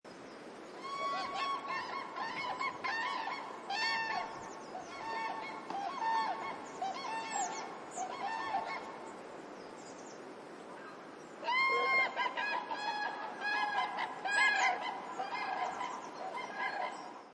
Kranich Ruf 1
Kranich-Ruf-Voegel-in-Europa-1.mp3